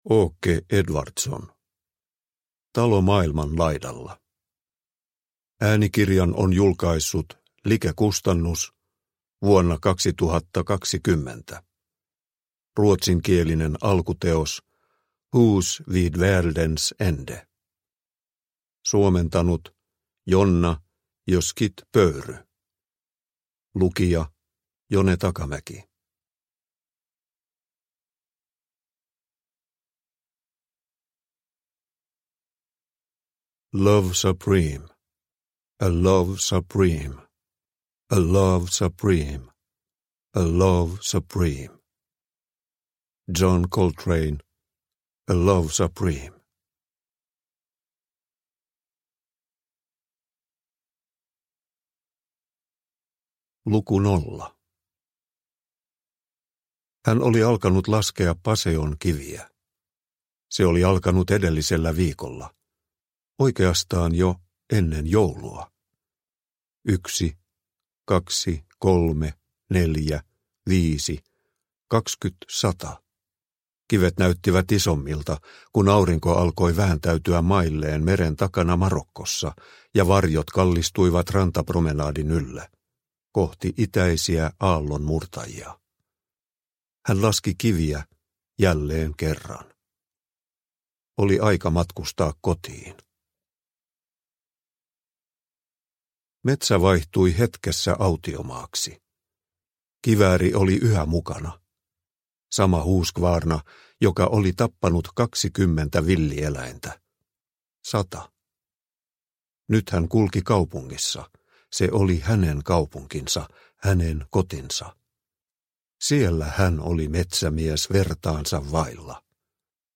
Talo maailman laidalla – Ljudbok – Laddas ner